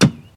axe_hit.ogg